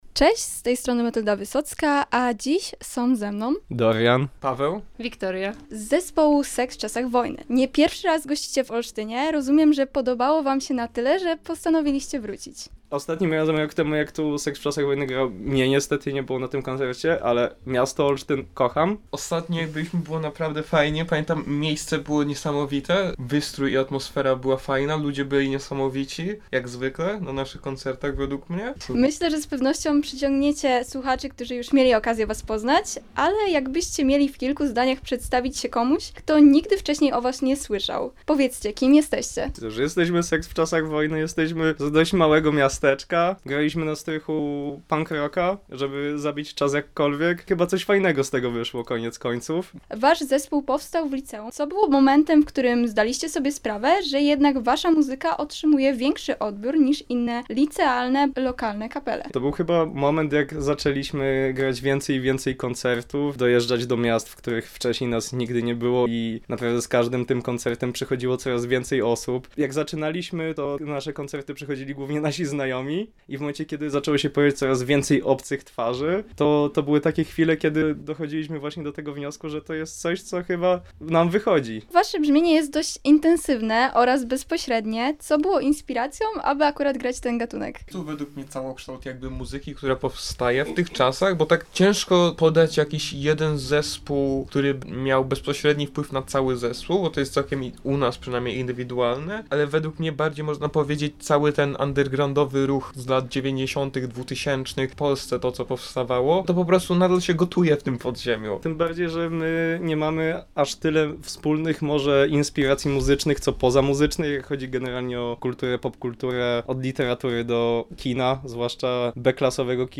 Mamy nadzieję, że jak najszybciej tutaj wrócimy  – mówią muzycy zespołu Seks W Czasach Wojny, który pomimo zaledwie kilkuletniego stażu już dwukrotnie zagrał w Olsztynie.